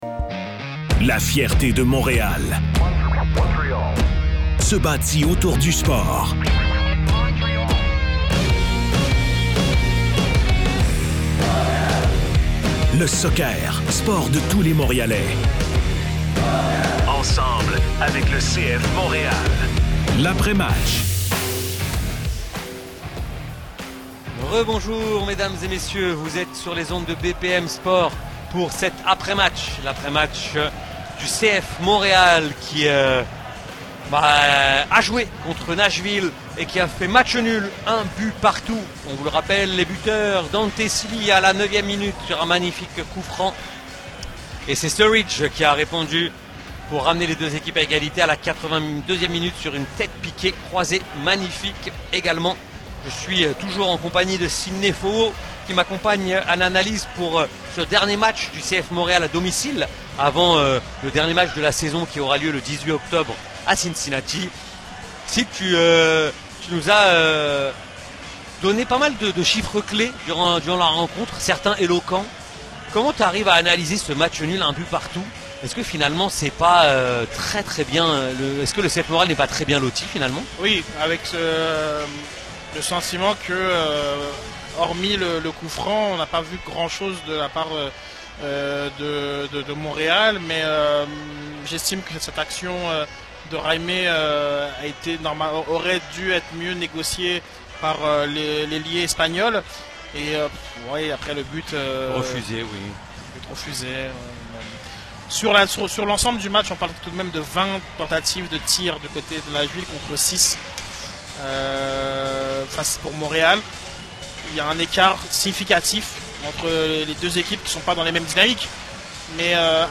point de presse